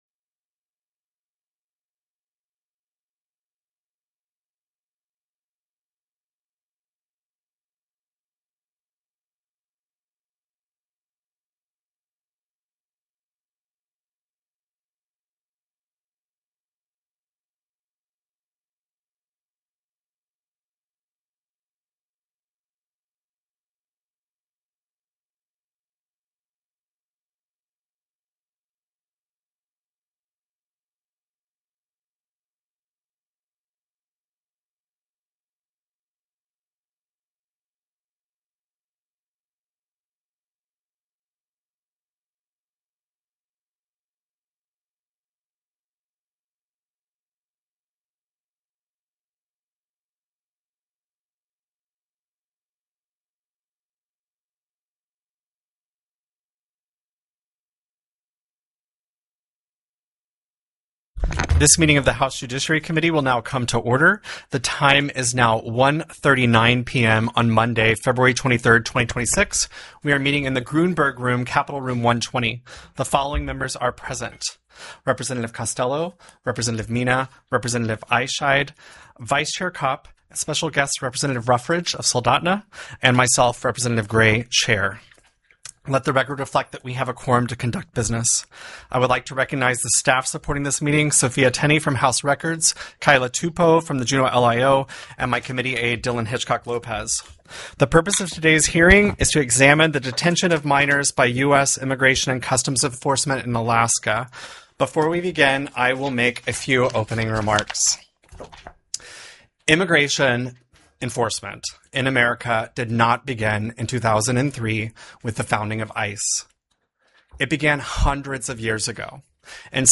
The audio recordings are captured by our records offices as the official record of the meeting and will have more accurate timestamps.
Investigative Hearing: Detention of Minors by TELECONFERENCED ICE in Alaska